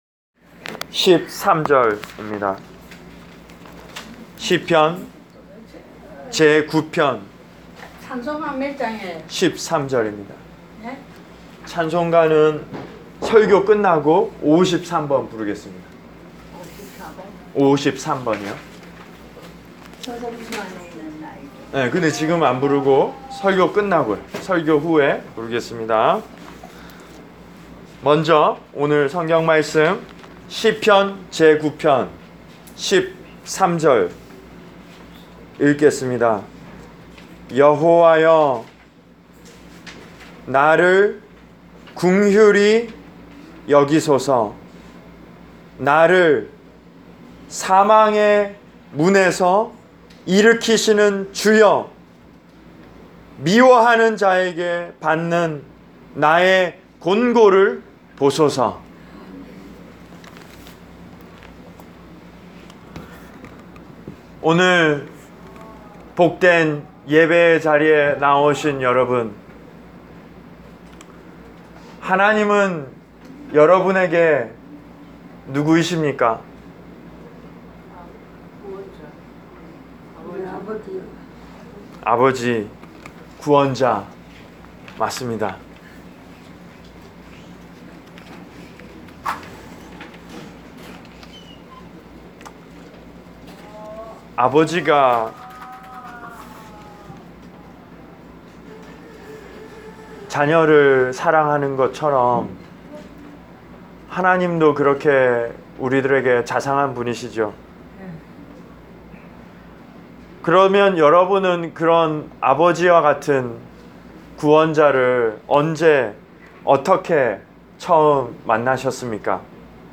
Preached for: Hudson View Rehab Center, North Bergen, N.J.